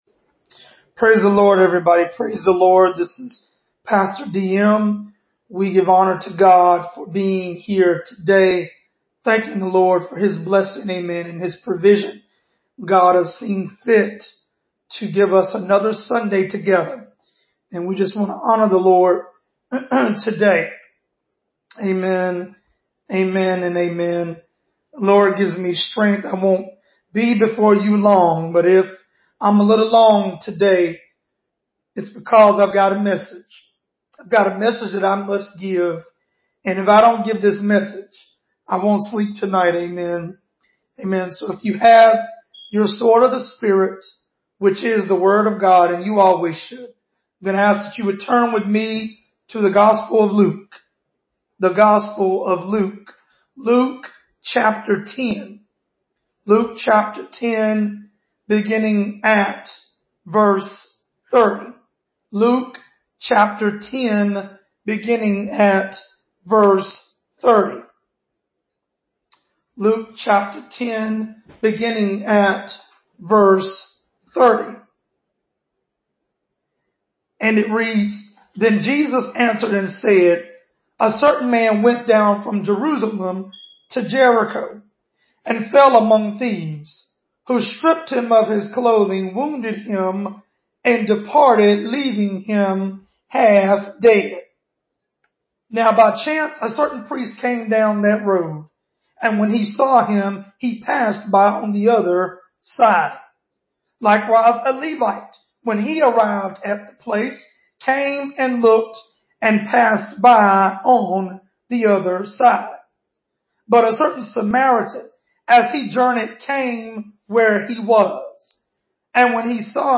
The Sin Of Doing Nothing, Luke 10:30-37 (Sermon)